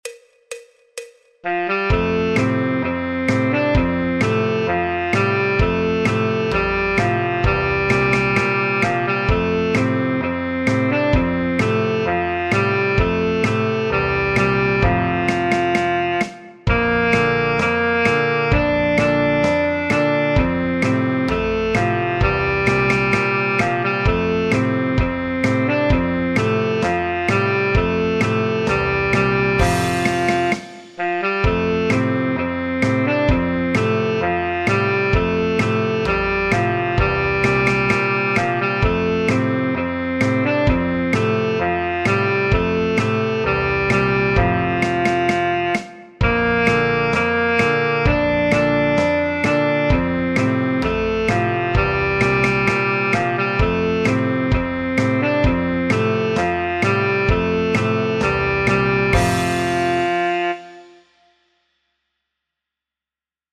El MIDI tiene la base instrumental de acompañamiento.
Saxofón Tenor / Soprano Sax
Sol Mayor
Folk, Popular/Tradicional